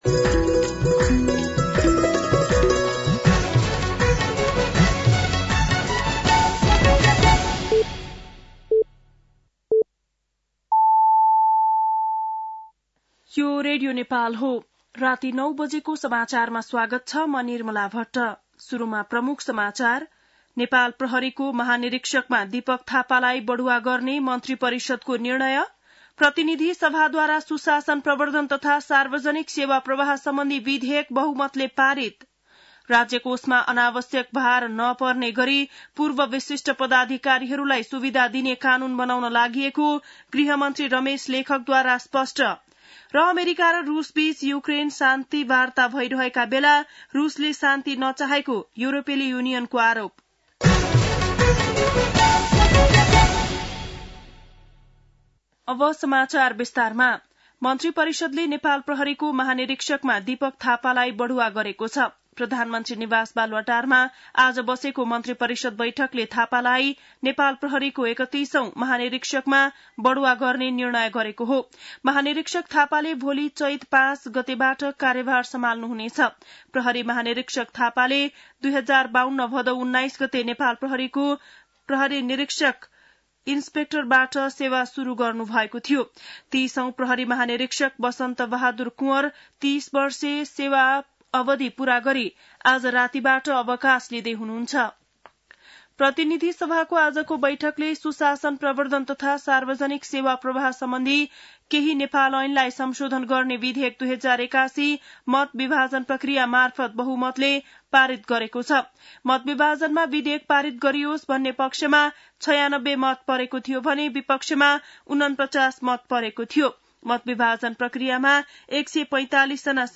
बेलुकी ९ बजेको नेपाली समाचार : ४ चैत , २०८१
9-PM-Nepali-NEWS-12-04.mp3